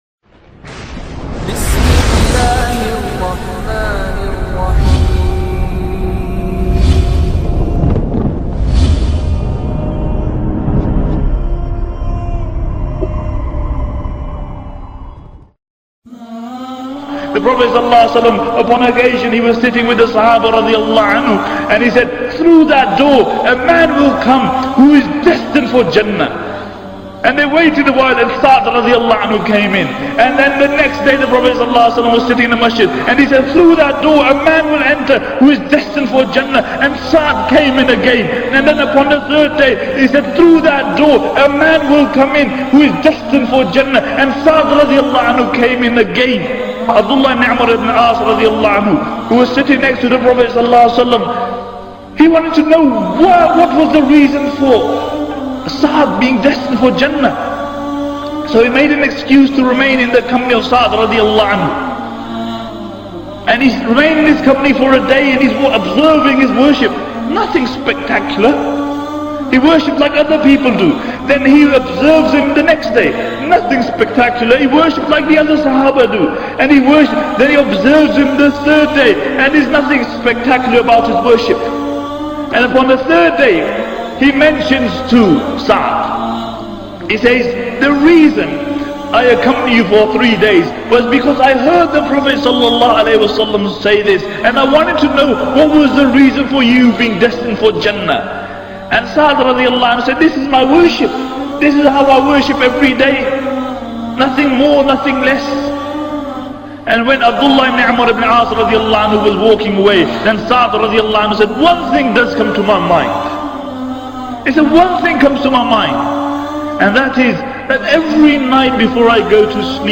A short reminder
apologies for the loud nasheed in the background